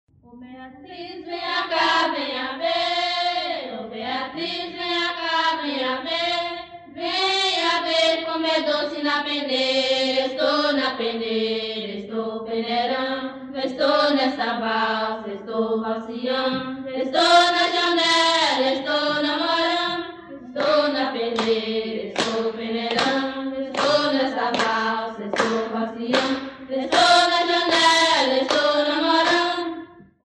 Canto de roda
Atividade musical vocal e infantil, de caráter lúdico e coreográfico. Pode ser acompanhada por palmas e bater de pés. É realizada por crianças nas brincadeiras de roda.
Termo Genérico Atividade musical Termos Associados Brincadeira de roda Objetos Digitais Canto de roda autor: Coral juvenil de Januária., data: 1960.
cantoderoda.mp3